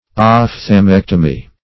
ophthalmectomy - definition of ophthalmectomy - synonyms, pronunciation, spelling from Free Dictionary